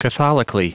Prononciation du mot catholically en anglais (fichier audio)
Prononciation du mot : catholically